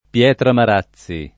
pL$tra mar#ZZi] (Piem.), ecc.